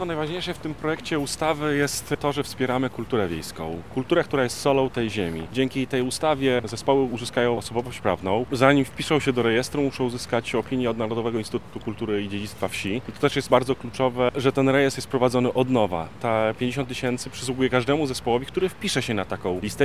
Mateusz Winiarski– mówi Mateusz Winiarski, Radny Powiatu Lubelskiego.